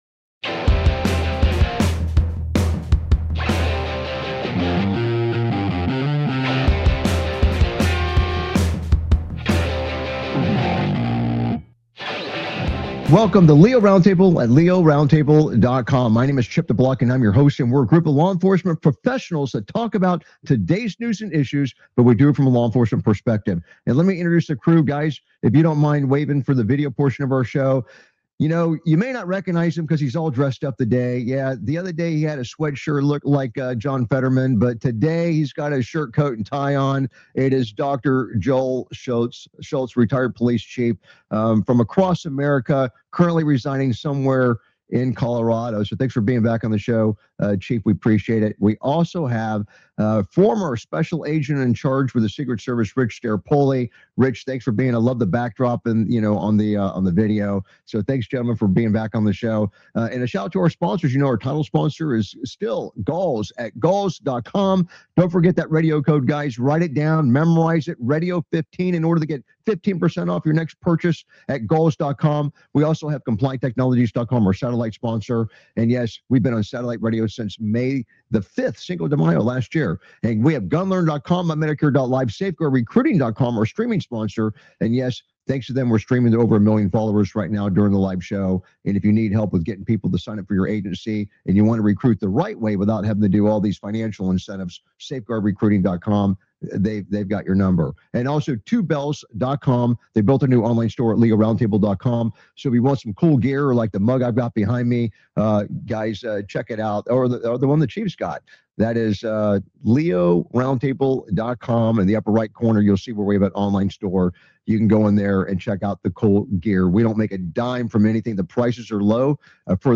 Talk Show Episode, Audio Podcast, LEO Round Table and S11E049, Police Chase Ends In Crash And Wild Shootout With Violent Murder Suspect!